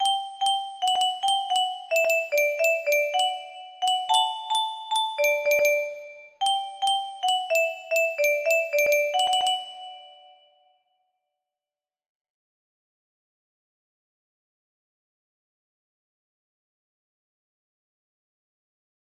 well music box melody